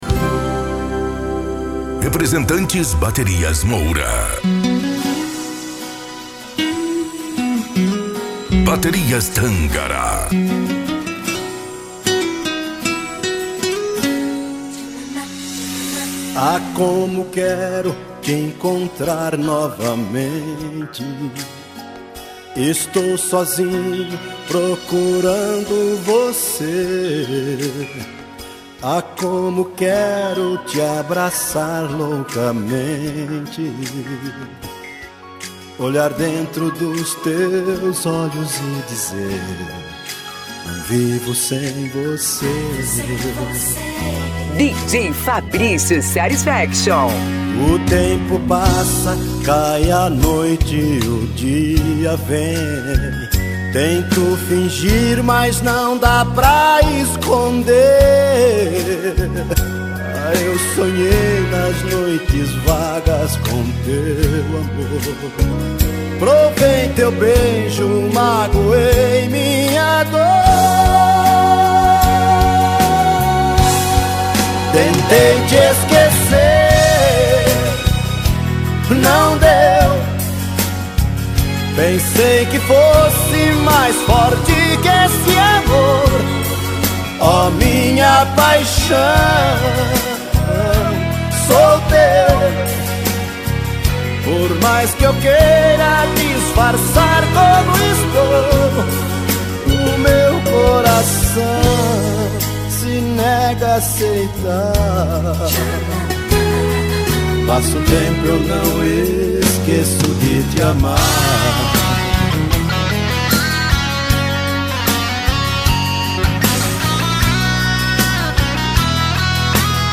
Modao